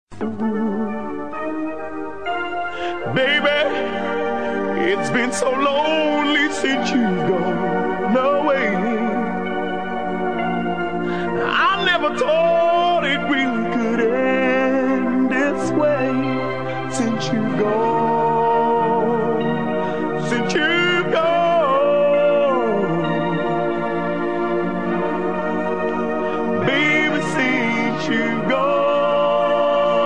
Голос певца очень знаком, но вспомнить не получается.
На Тома Джонса смахивает.